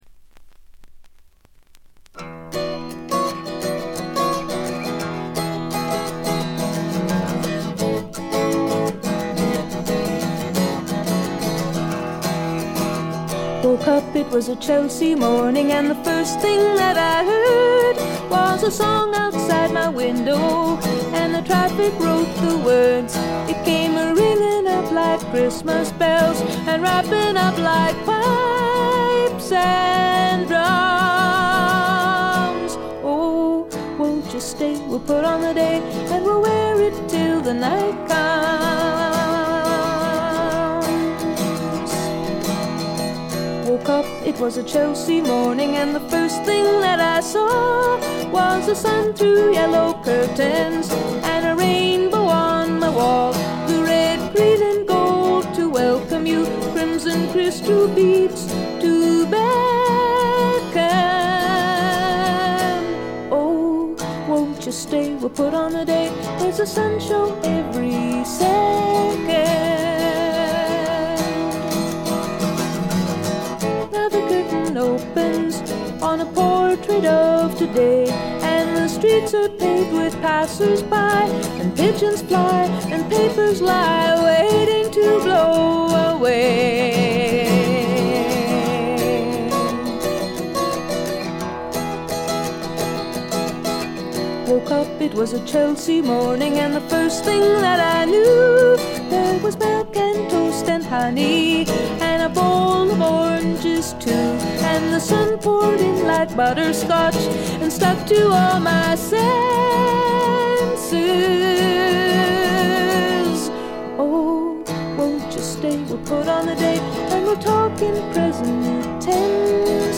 ところどころで軽微なバックグラウンドノイズに気づく程度。
透明感のあるみずみずしさが初期の最大の魅力です。
女性フォーク／シンガーソングライター・ファンなら避けては通れない基本盤でもあります。
試聴曲は現品からの取り込み音源です。
guitar, keyboards, vocals
bass, guitar